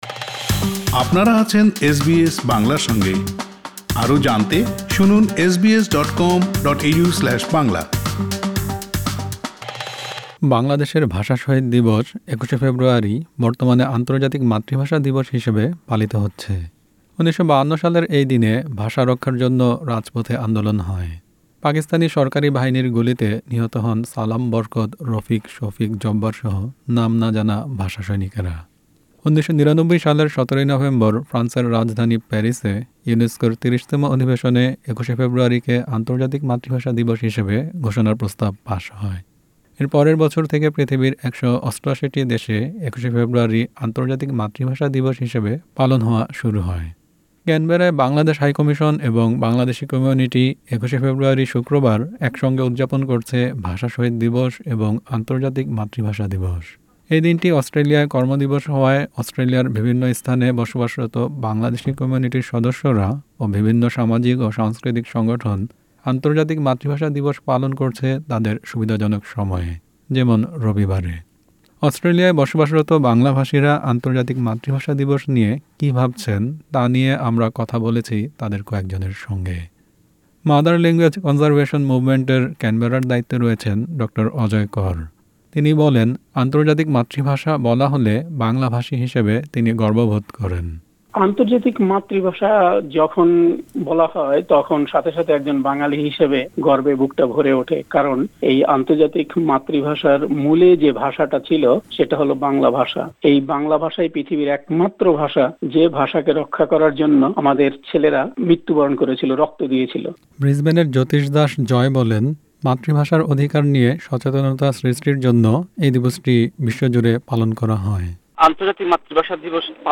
আন্তর্জাতিক মাতৃভাষা দিবস নিয়ে এসবিএস বাংলার সঙ্গে কথা বলেছেন অস্ট্রেলিয়ায় বসবাসরত বাংলাভাষী সম্প্রদায়ের কয়েকজন।